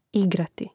ìgrati igrati